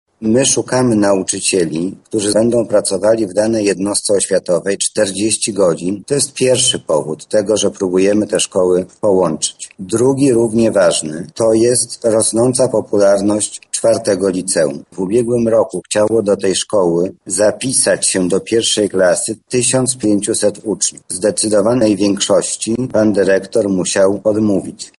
O powodach podjęcia decyzji o połączeniu placówek mówi Mariusz Banach, zastępca prezydenta ds. oświaty i wychowania: